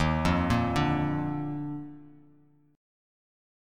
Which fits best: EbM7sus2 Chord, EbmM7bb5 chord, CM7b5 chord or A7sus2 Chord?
EbM7sus2 Chord